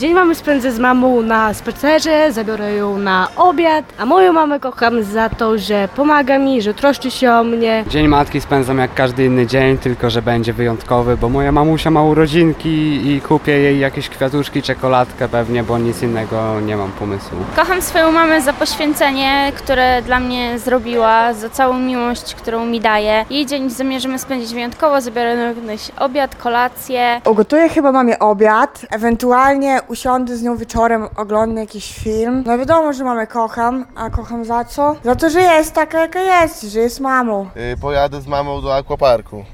To dobra okazja, aby podziękować mamom za ich trud włożony w wychowanie dzieci. Zapytaliśmy mieszkańców Suwałk, za co kochają najważniejsze kobiety w swoim życiu i jak zamierzają obchodzić to święto.